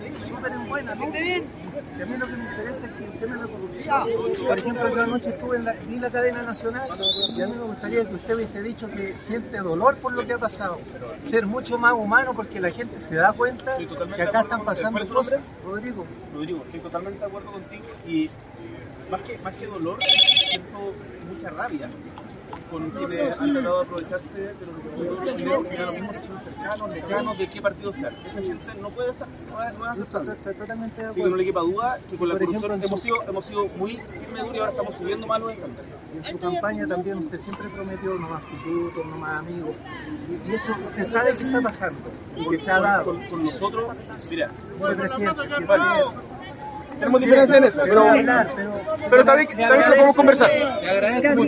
En el Día mundial sin auto, pasada las 11:00 horas de esta mañana de viernes, el presidente Gabriel Boric llegó en bicicleta hasta La Moneda y se dirigió a saludar a personas que lo estaban esperando en la Plaza de la Constitución.
En la instancia, el jefe de Estado mantuvo un breve diálogo con un hombre que se acercó a hablarle de los casos que se están investigando por posible delito de corrupción.